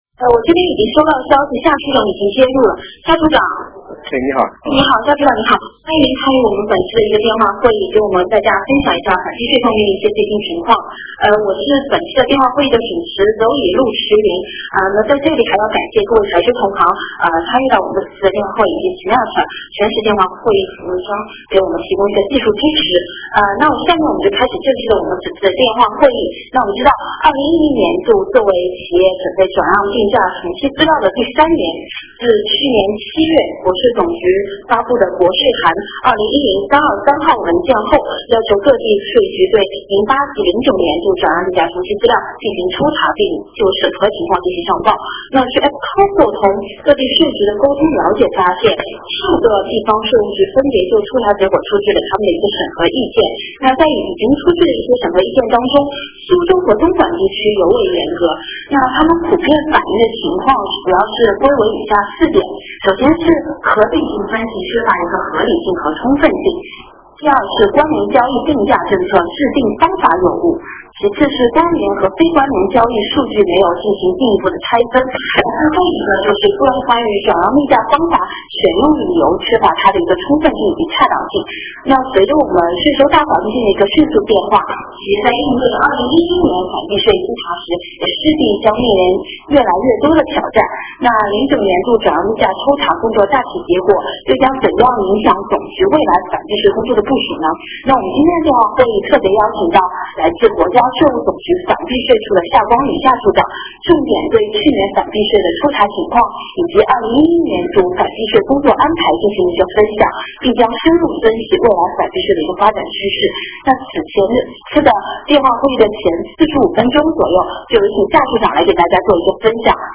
电话会议
互动问答